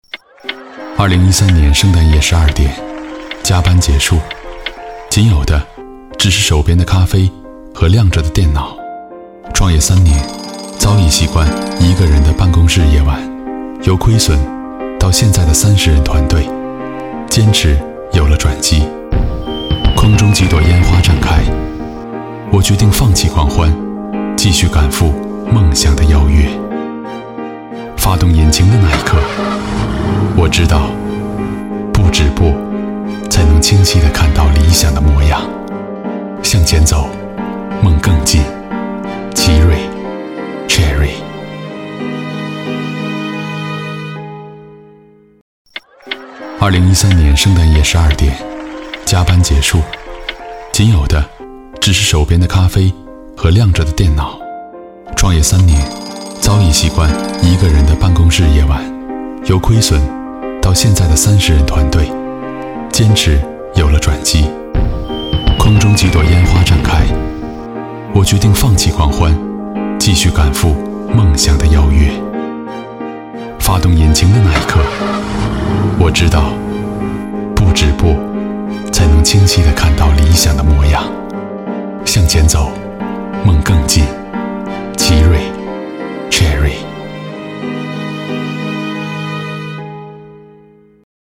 国语青年低沉 、调性走心 、感人煽情 、男微电影旁白/内心独白 、200元/分钟男11 国语 男声 微电影旁白 独白 北疆天路 消失72小时 歌手篇-01 低沉|调性走心|感人煽情 - 样音试听_配音价格_找配音 - voice666配音网
国语青年低沉 、调性走心 、感人煽情 、男微电影旁白/内心独白 、200元/分钟男11 国语 男声 微电影旁白 独白 北疆天路 消失72小时 歌手篇-01 低沉|调性走心|感人煽情